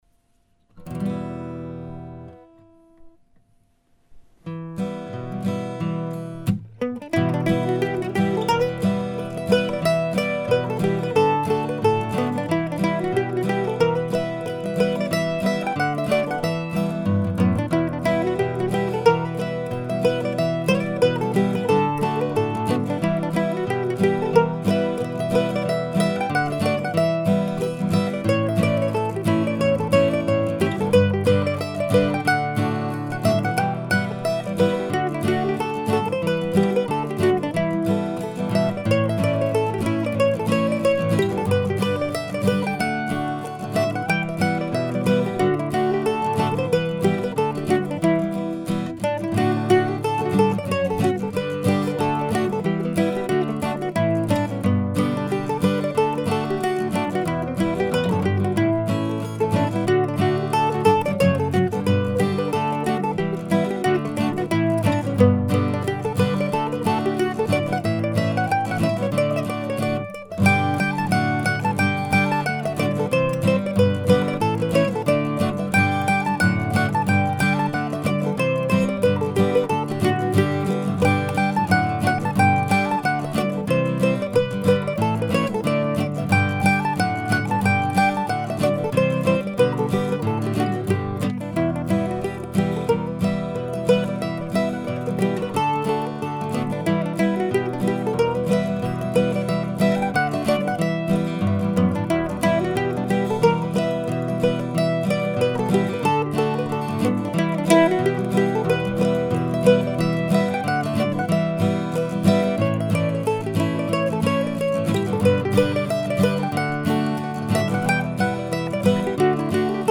I was listening to Norman and Nancy Blake on my mp3 player and wrote this four part tune not long after.